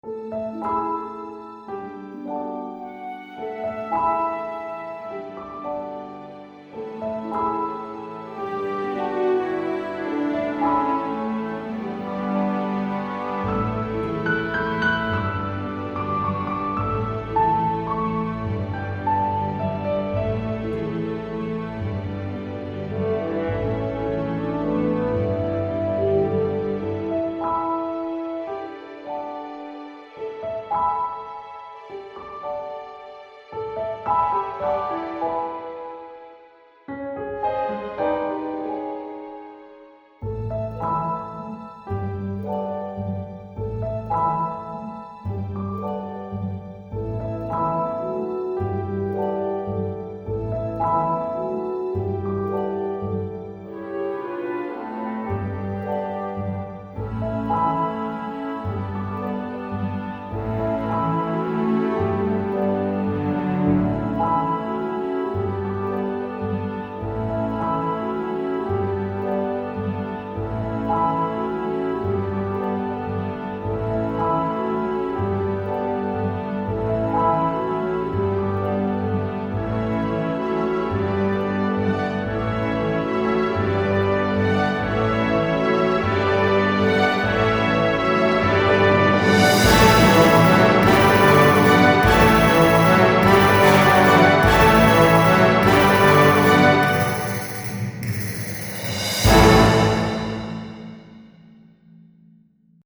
tu peux même développer ton petit thème au piano